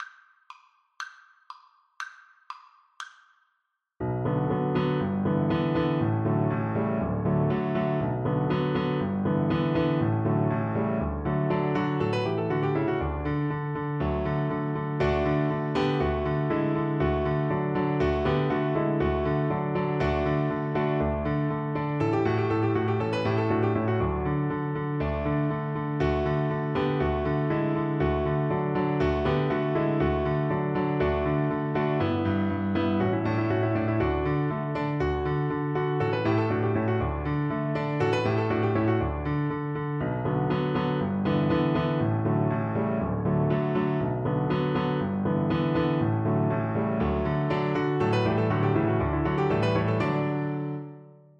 Cello
D major (Sounding Pitch) (View more D major Music for Cello )
2/4 (View more 2/4 Music)
Allegro (View more music marked Allegro)
Classical (View more Classical Cello Music)